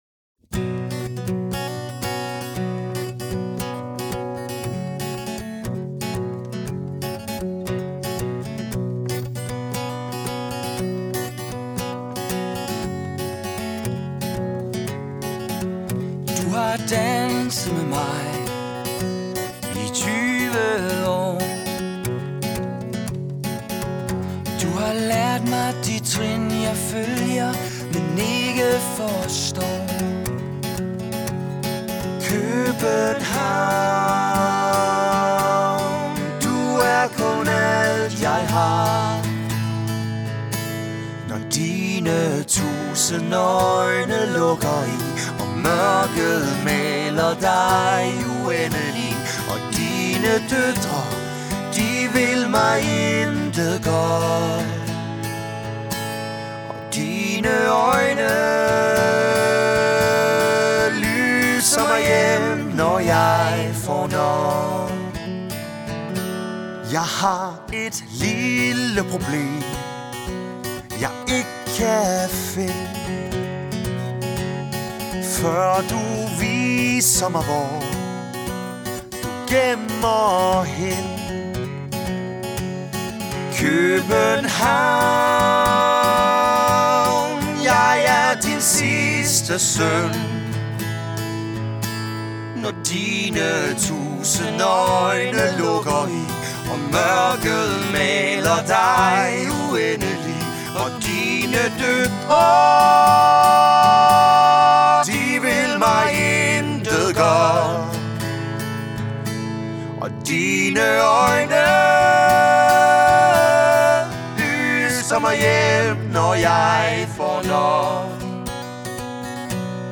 to erfarne musikere, der spiller live musik til din fest.
• Duo eller trio